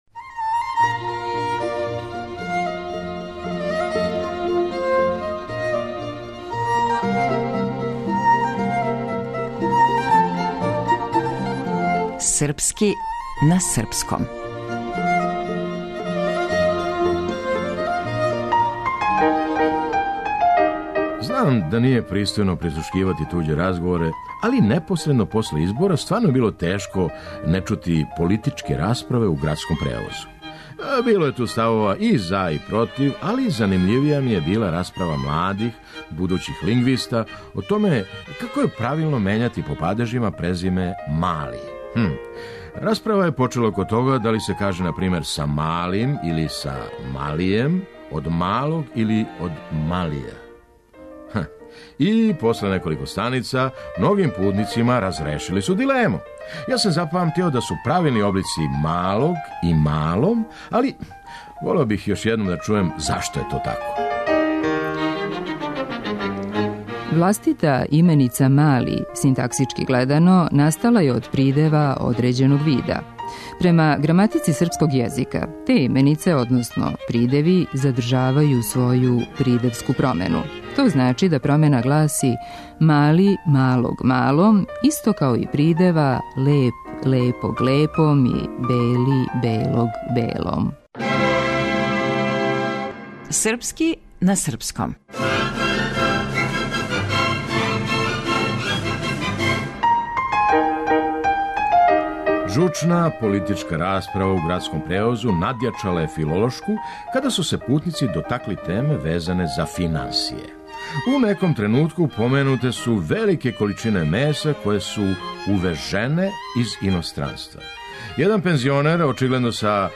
Драмски уметник: Феђа Стојановић.